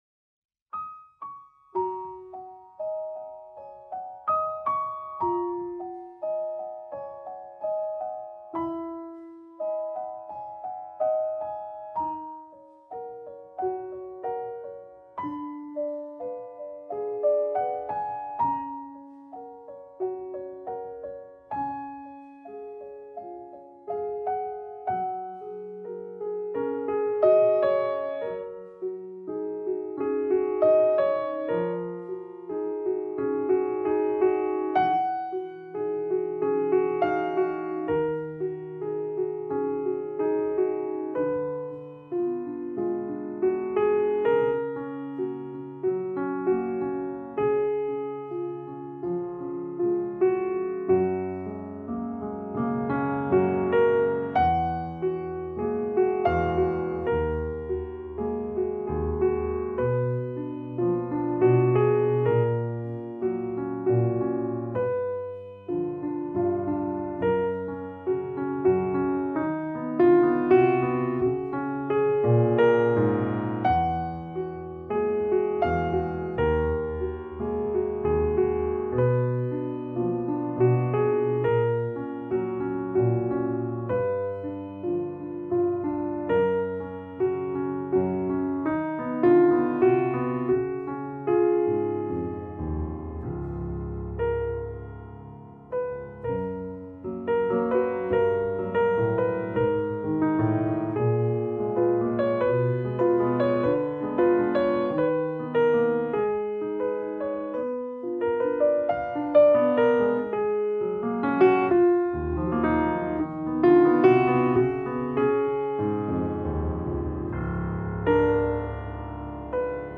Жанр: Alternativa.